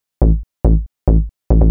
Techno / Bass
1 channel